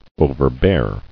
[o·ver·bear]